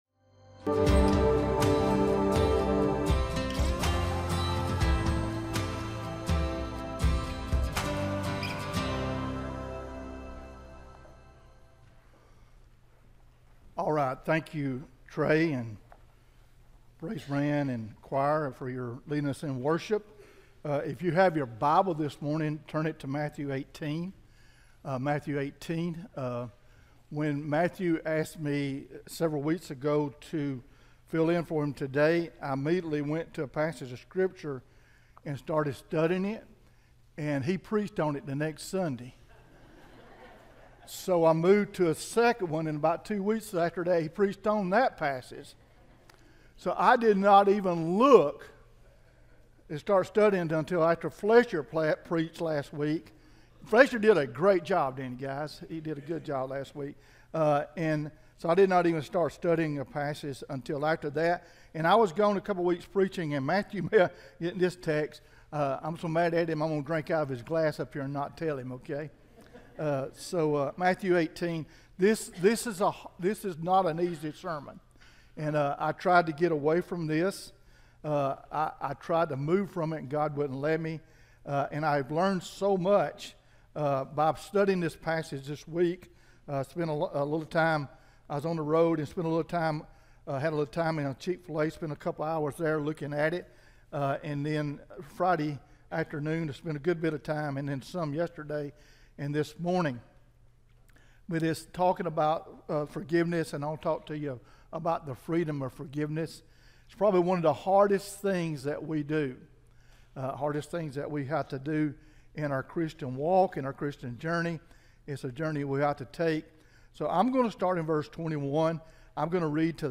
Sermon-5-26-24-audio-from-video.mp3